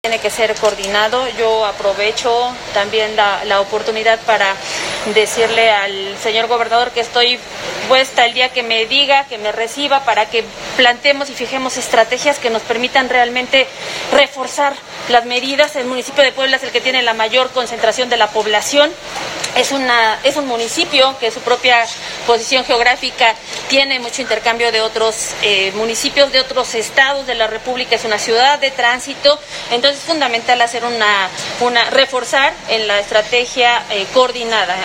En entrevista, Rivera Vivanco reiteró su disponibilidad para sumarse a las mesas de trabajo que se efectúen por parte del gobierno del estado para la reubicación que se busca del ambulantaje, así como de recobrar el rubro de movilidad que han acaparado comerciantes informales.